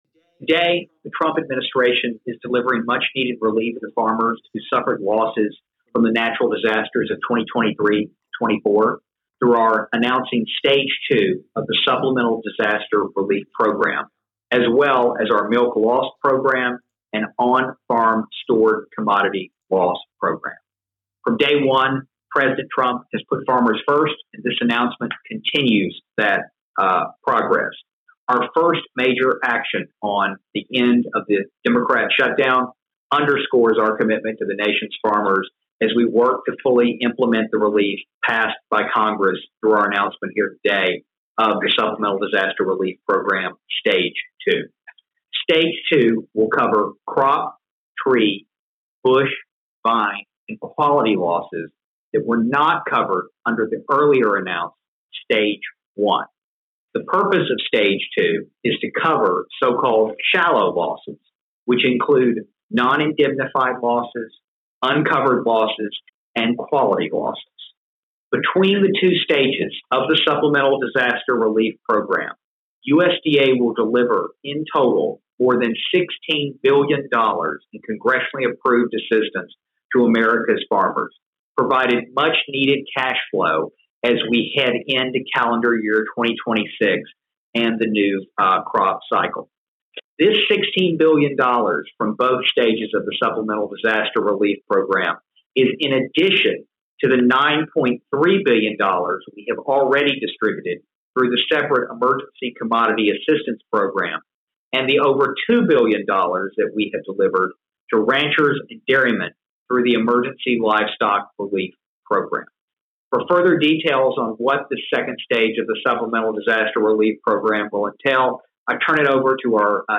***AUDIO*** Hear comments with Deputy Ag Secretary Stephen Vaden and Undersecretary for Farm Production and Conservation Richard Fordyce on a Monday morning press call announcing the news: